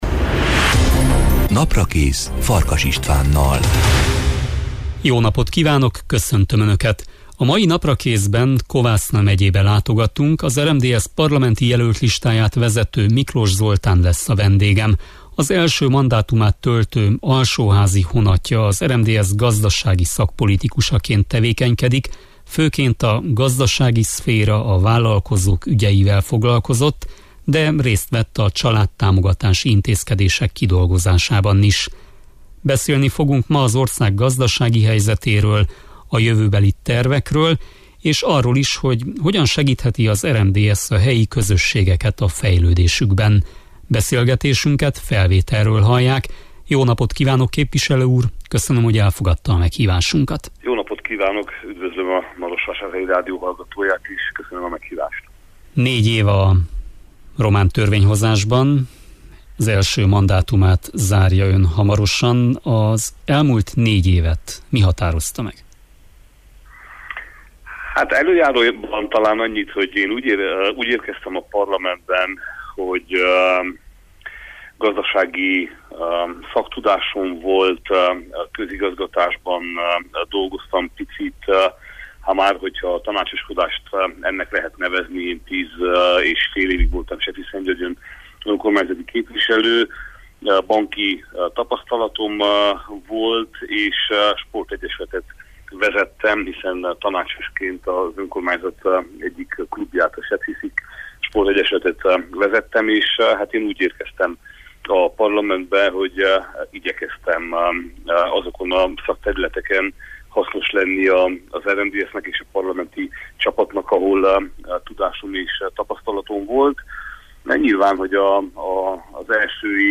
Az RMDSZ parlamenti jelöltlistáját vezető Miklós Zoltán lesz a vendégem.